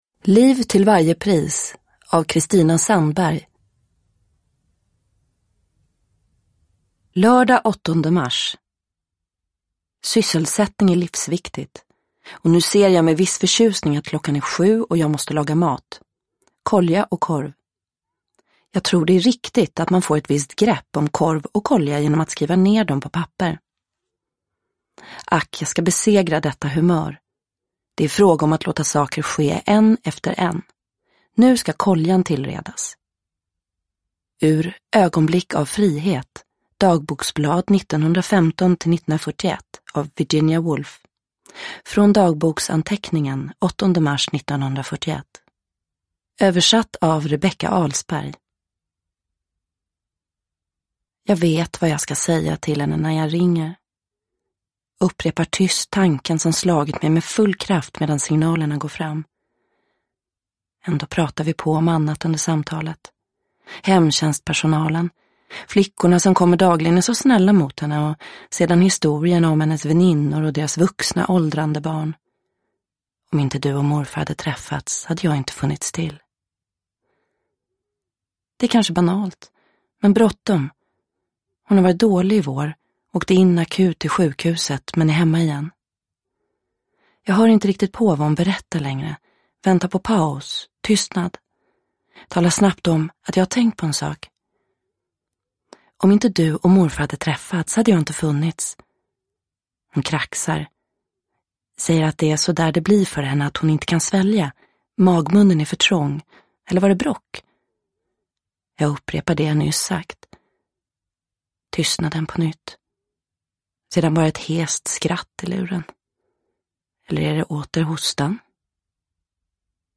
Liv till varje pris – Ljudbok – Laddas ner